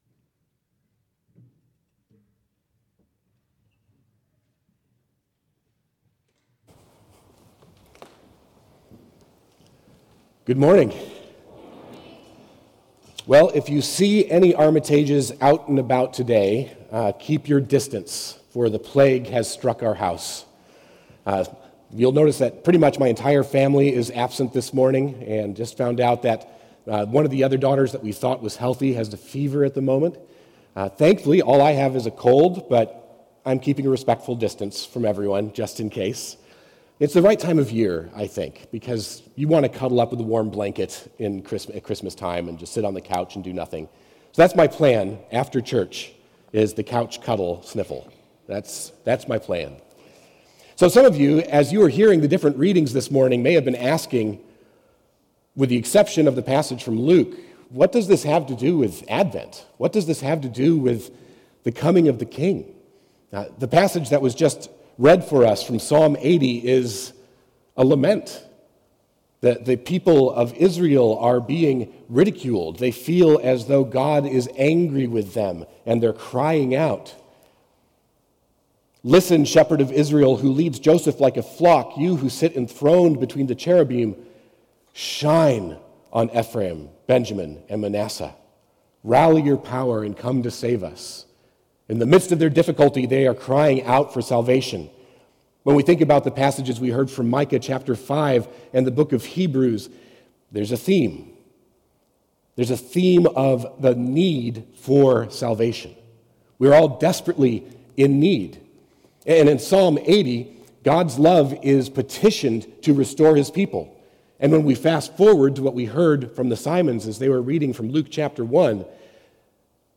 Hear expository sermons from the teaching team of Trinity Fellowship Church in Richardson, Texas. Moving together into the remarkable love of Jesus Christ.